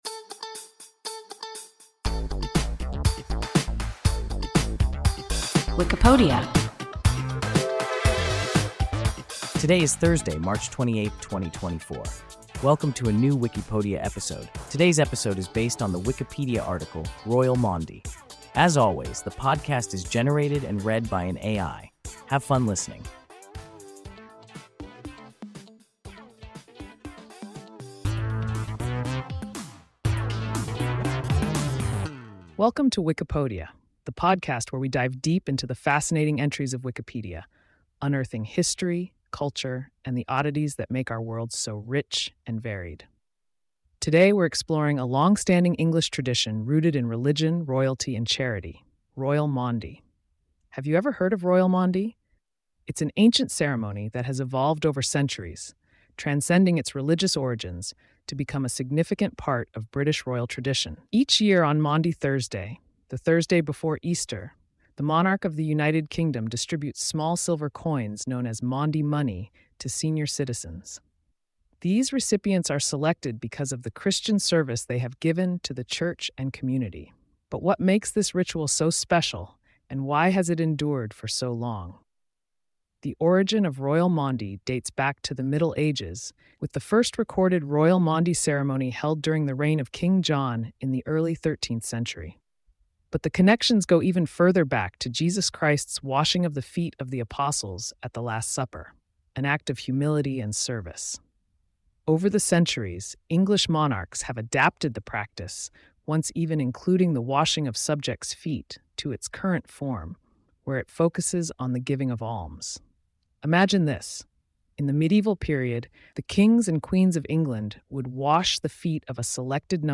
Royal Maundy – WIKIPODIA – ein KI Podcast